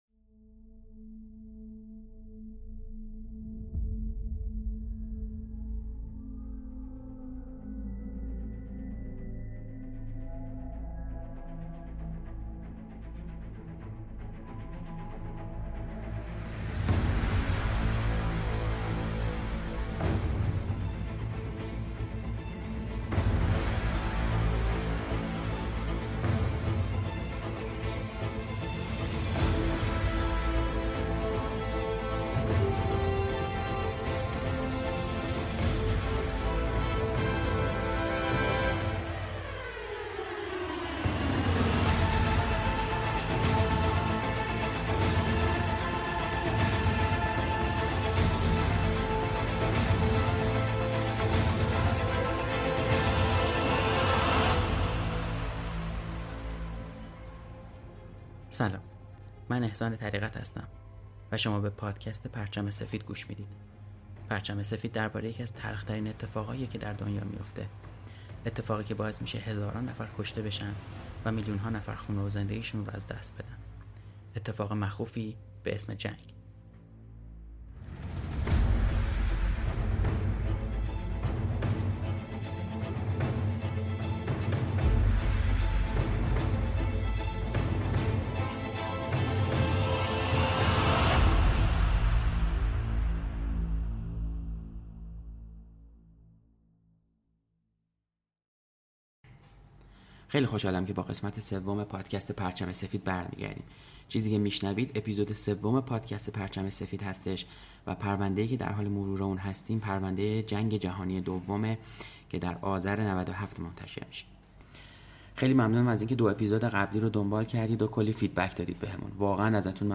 موسیقی ابتدا و انتها